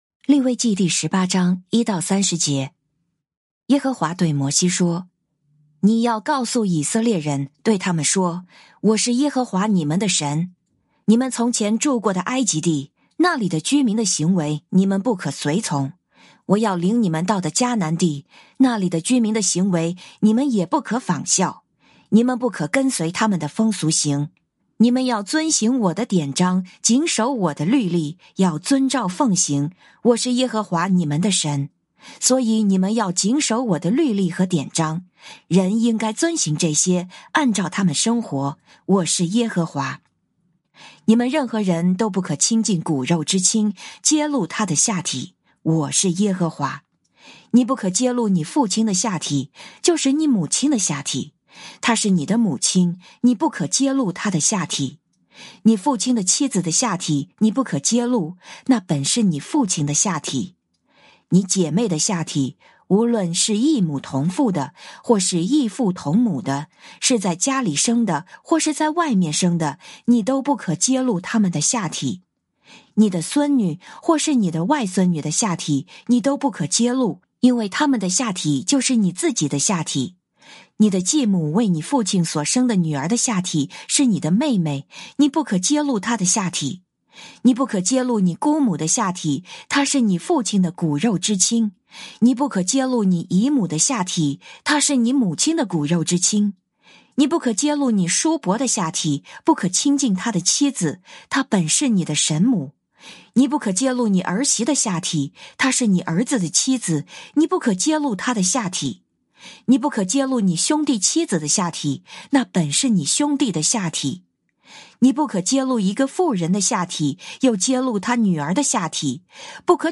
「天父爸爸說話網」是由北美前進教會Forward Church 所製作的多單元基督教靈修音頻節目。